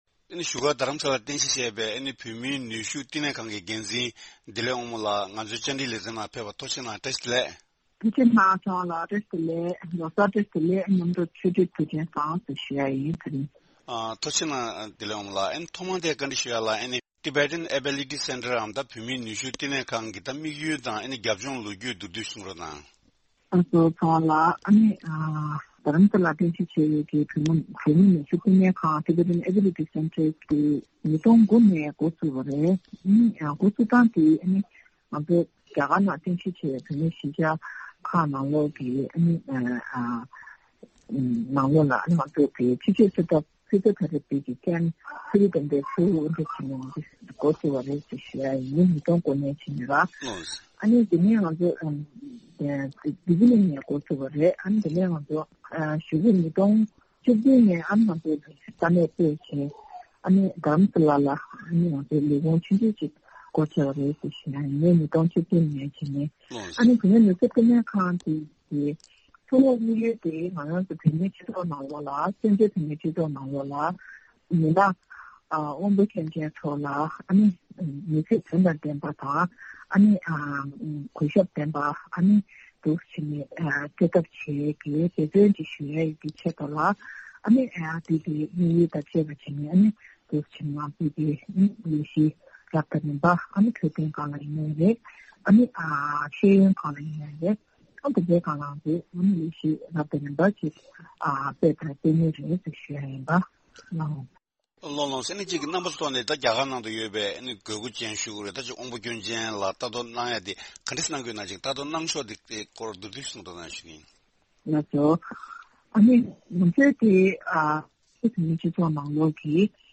ཞིབ་ གསལ་གནས་འདྲི་ཞུས་པ་ཞིག་གསན་རོགས་གནང་།